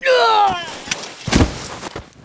男声死亡倒地zth070518.wav
通用动作/01人物/04人的声音/死亡/男声死亡倒地zth070518.wav